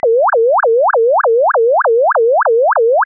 (Fig. 5h): NOTAP result using linear interpolation, an oversampling ratio, N = 50, and the receiver sampling frequency of 4 kHz.